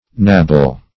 Search Result for " knabble" : The Collaborative International Dictionary of English v.0.48: Knabble \Knab"ble\ (n[a^]b"b'l), v. i. [Freq. of knab.] To bite or nibble.